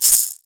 Shaken Maracas 02.wav